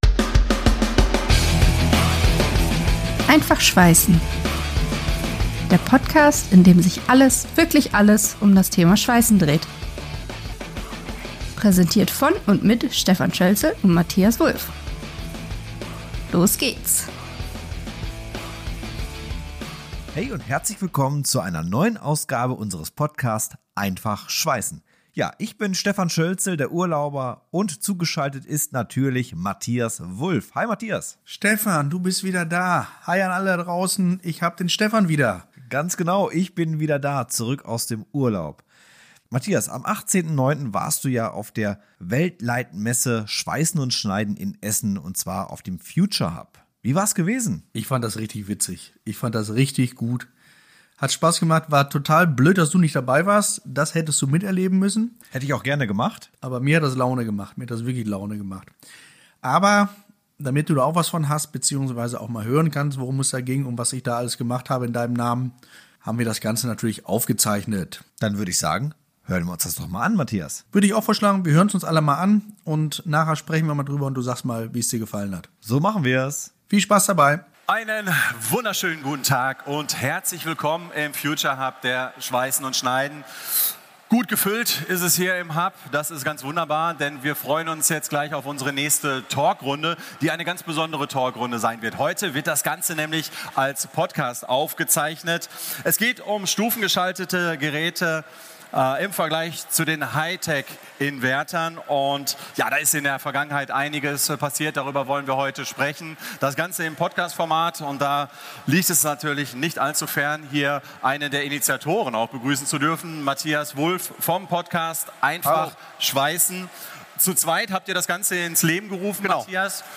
Heute gibt's den versprochenen Mitschnitt von unserer Podiumsdiskussion auf der Schweissen & Schneiden in Essen im September. Definitiv ein besonderer Moment und darum hier für euch die gesamte Podiumsdiskussion als Live-Take.